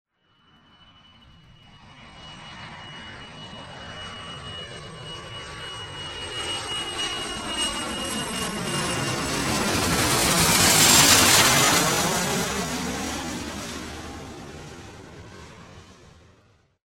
ジェット機の通過音 着信音
ゴーといった飛び去る音。轟音と共に上空を飛び去るジェット機の音。